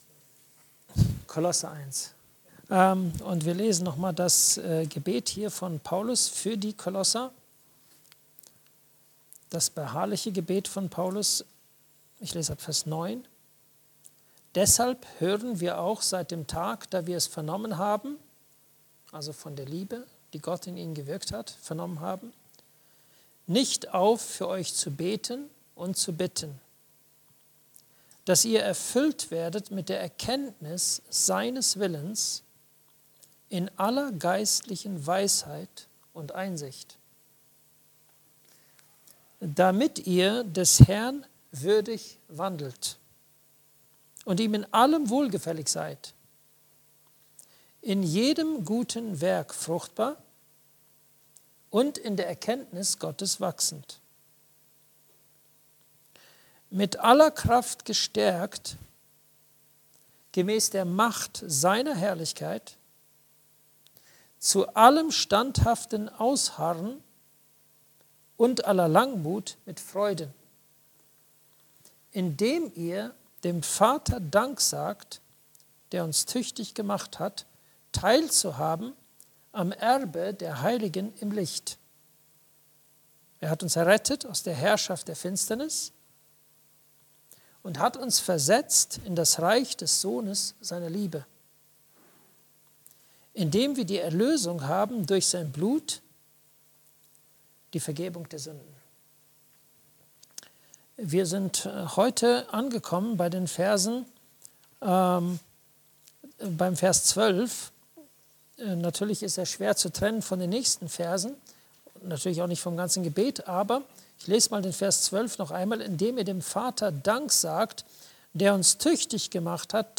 Dem Vater Dank sagen, der uns tüchtig gemacht hat (Andacht Gebetsstunde)